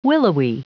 Prononciation du mot willowy en anglais (fichier audio)
Prononciation du mot : willowy